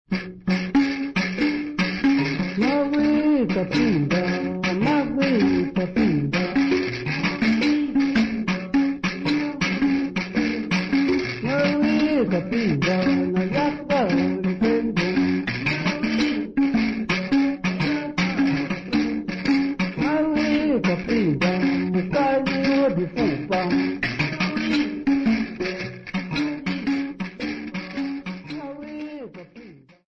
Sambiu church music workshop participants
Sacred music Namibia
Mbira music Namibia
Africa Namibia Sambiu mission, Okavango sx
field recordings
Church song accompanied by the mbira type instrument sisanti and indingo played at both lower and upper key.